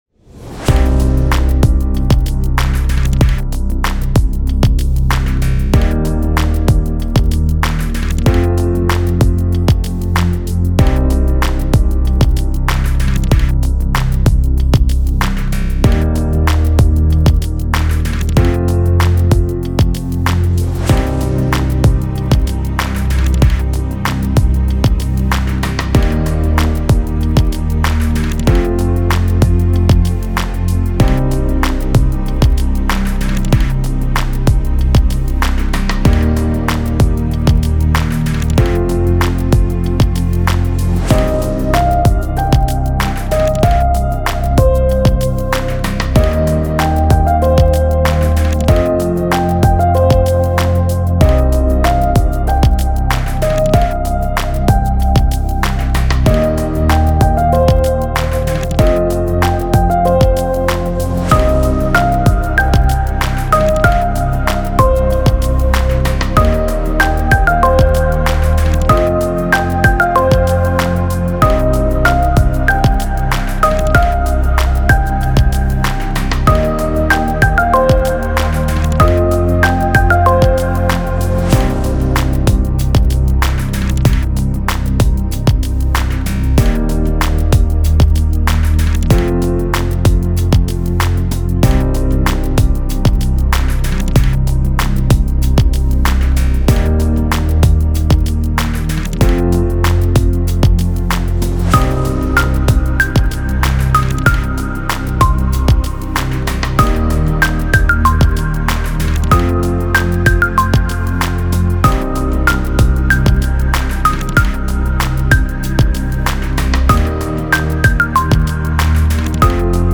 AmbientPianoBackground.mp3